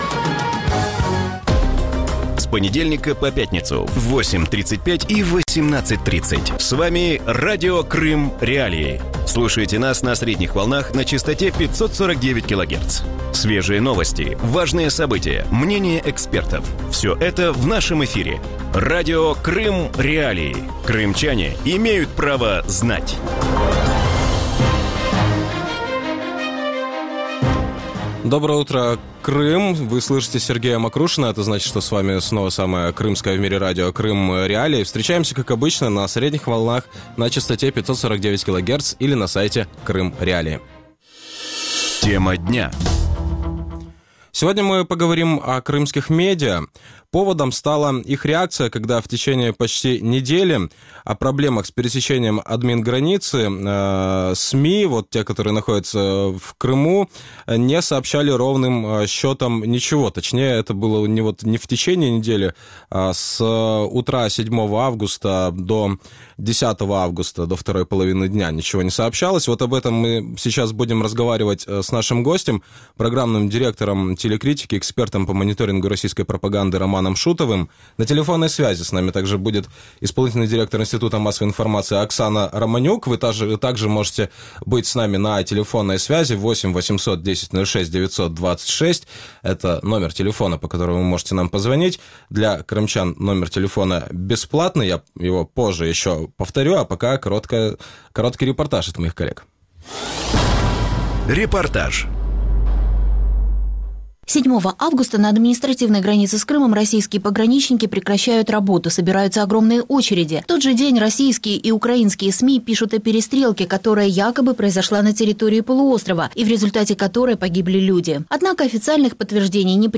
Вранці в ефірі Радіо Крим.Реалії говорять про висвітлення кримськими медіа нещодавніх проблем із перетинанням адмінкордону із анексованим півостровом і подальші обвинувачення з боку російської ФСБ на адресу Києва в організації "диверсії" у Криму.